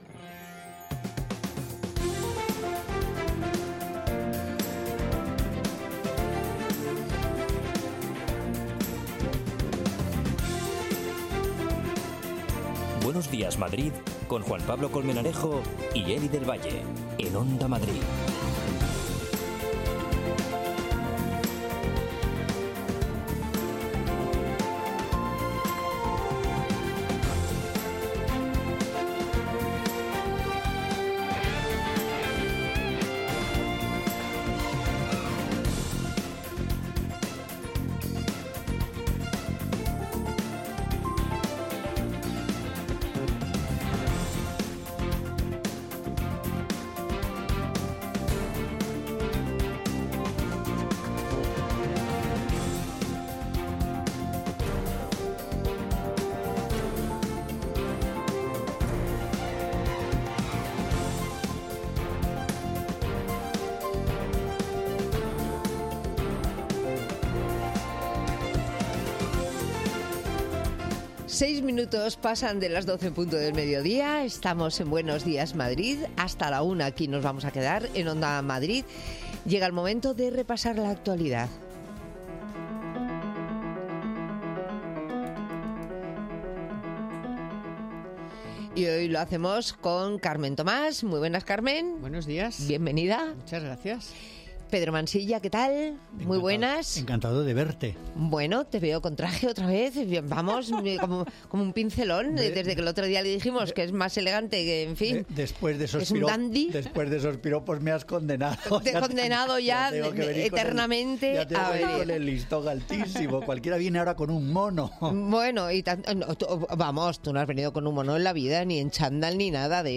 Como cada día en la tercera hora, tenemos nuestra Tertulia Social, comentando algunos de los asuntos del día. Por ejemplo, que los taxistas se están implicando cada vez más en la seguridad de quienes usamos sus servicios.
Nuestra unidad móvil ha estado con algunos de los taxistas que se han unido a esta iniciativa.